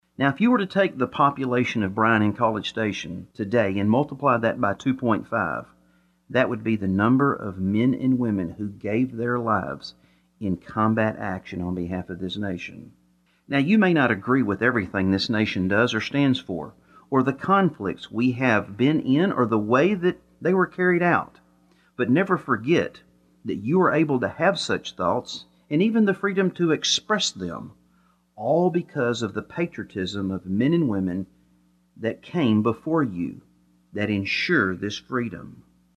As a result of the restrictions put in place by the coronavirus, activities that would normally take place at Brenham’s American Legion Hall occurred only over the airwaves of KWHI.